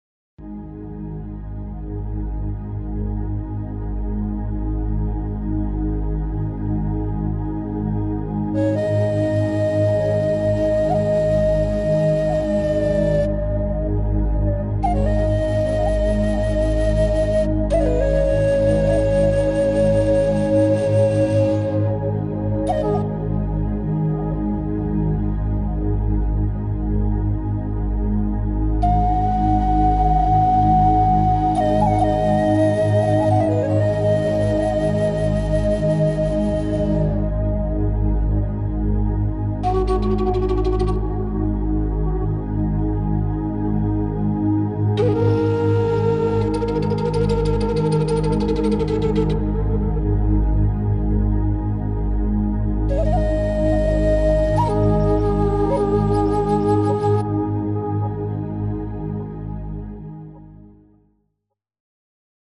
Largo [0-10] suspense - flute - - -